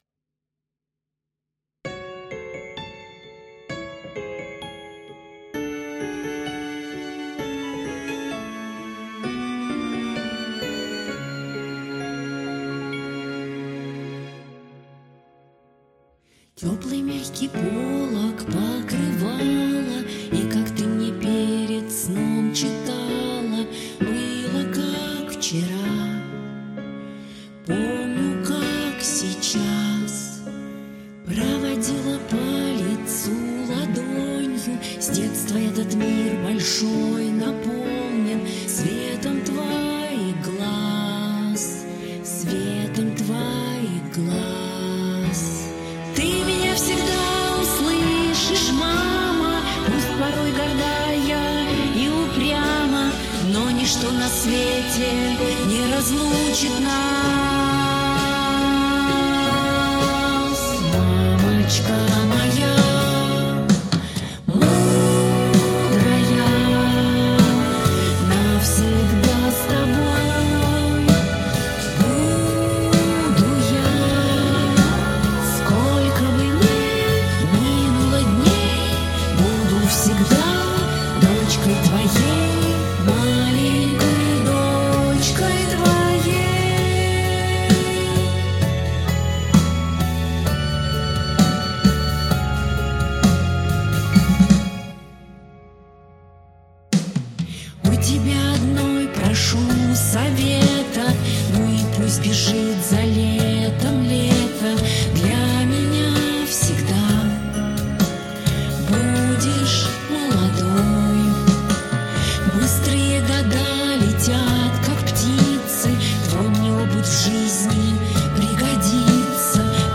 Микс на оценку
Стиль не люблю такой сам, попсово приторный, но тем не менее. Вокалистка начального уровня и что то с этим сделать с этим хз как.